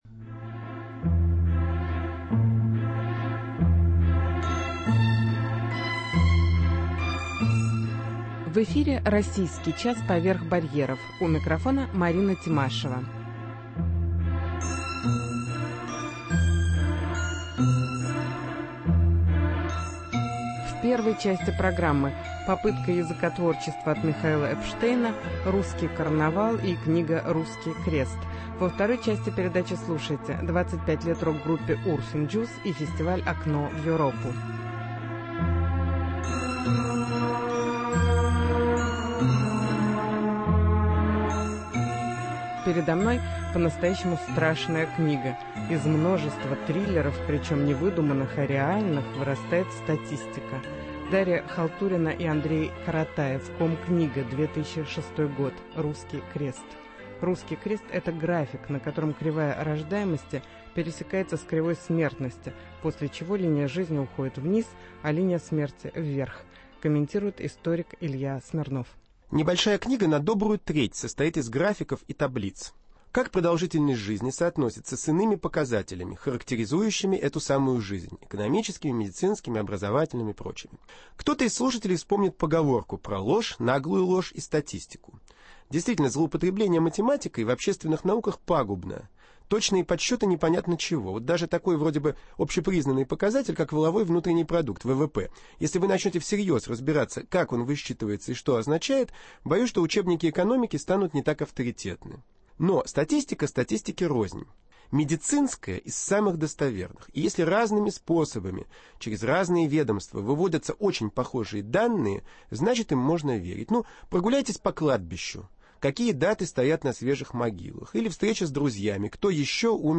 Выставка "Русский карнавал" - интервью с театральным художником Татьяной Сельвинской